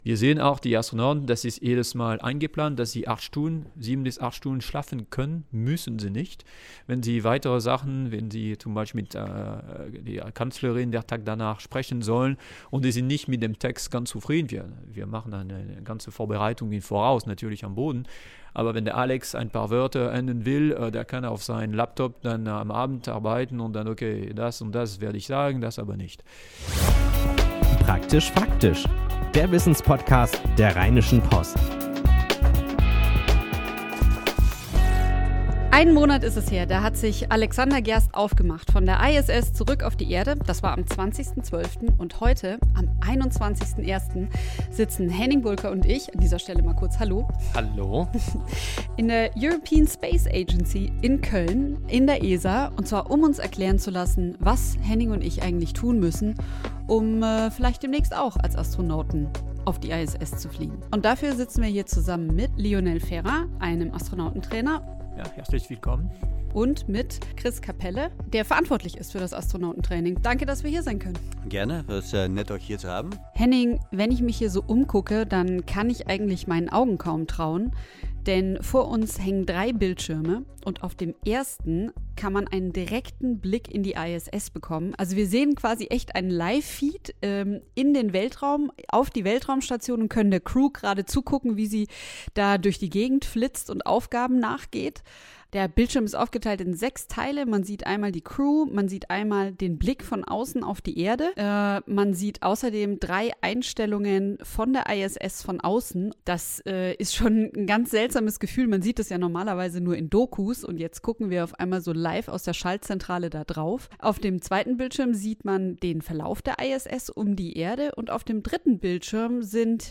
Wie sieht das Training für den Aufenthalt im Weltall aus? Und kann man auf der ISS eigentlich auch Serien gucken? Diese Fragen beantworten die beiden Astronauten-Trainer